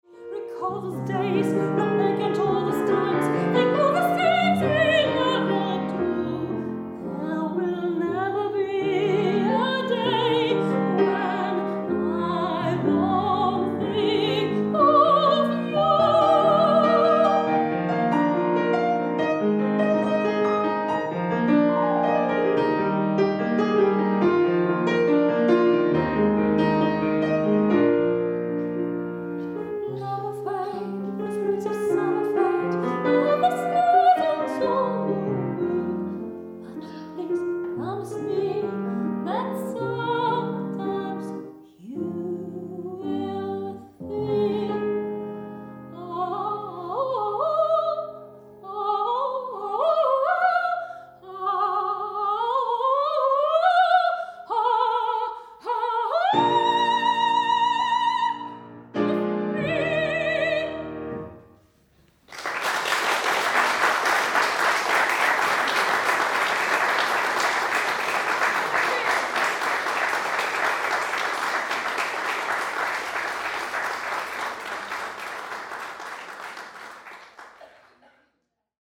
Hochzeitssängerin Hannover/Niedersachsen